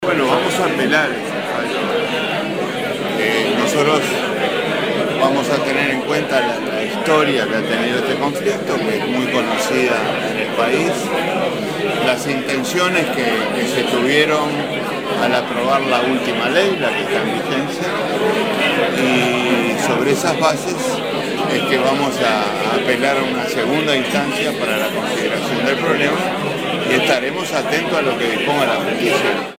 Astori dijo en rueda de prensa que irán a una segunda instancia, en el entendido que debe primar la ley votada en enero que estableció un incremento del 8% para los integrantes del Poder Judicial.